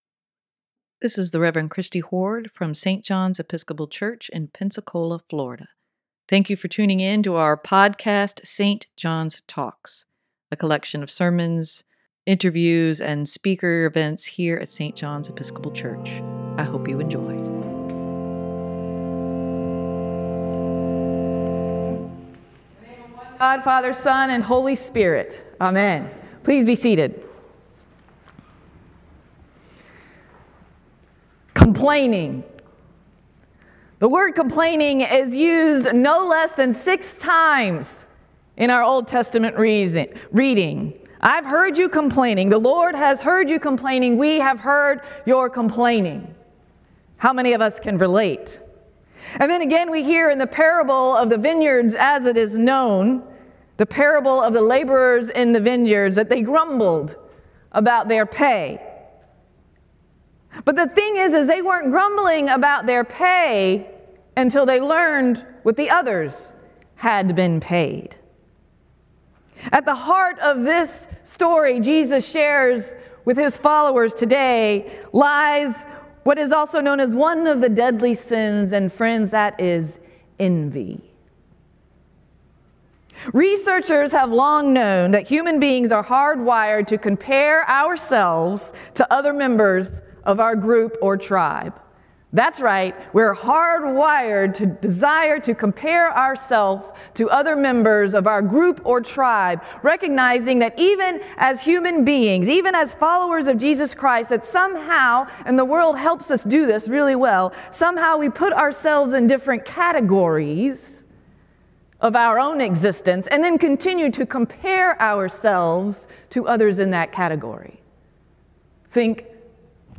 sermon-9-24-23.mp3